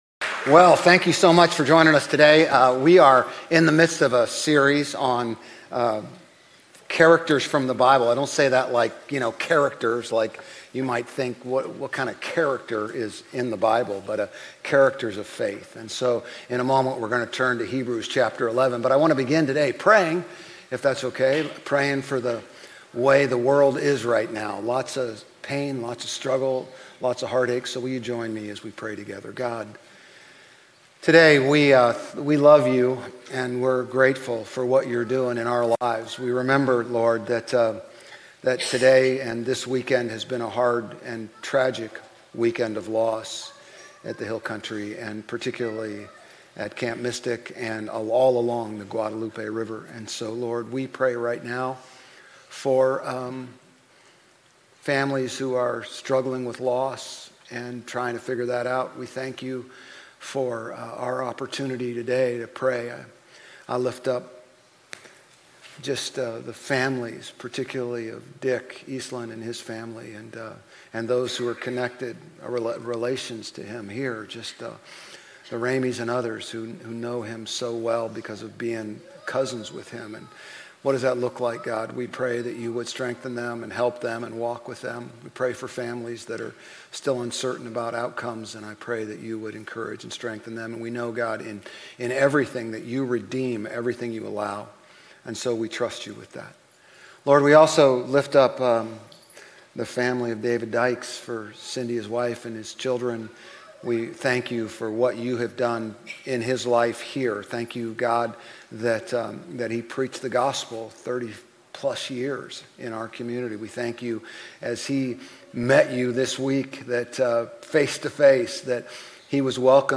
Grace Community Church Old Jacksonville Campus Sermons 7_6 Old Jacksonville Campus Jul 06 2025 | 00:34:28 1x 00:00 / 00:34:28 Subscribe Share RSS Feed Share Link Embed